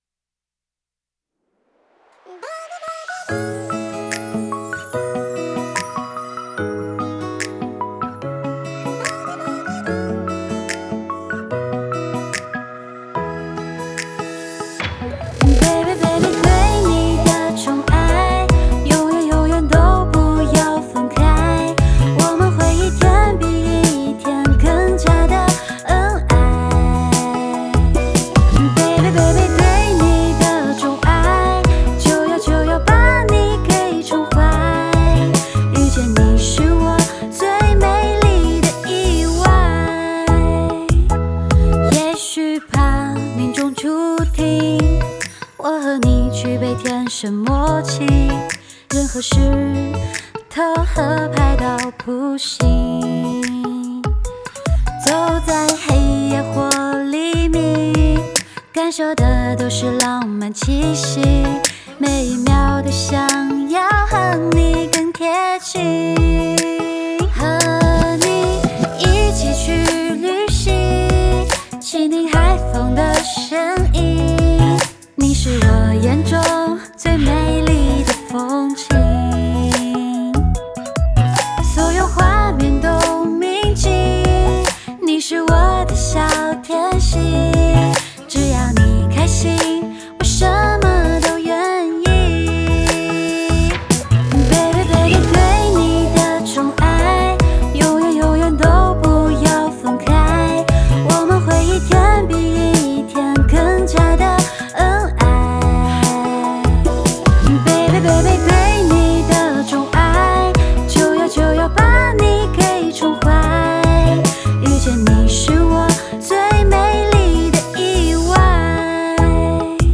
Ps：在线试听为压缩音质节选，体验无损音质请下载完整版
和声：200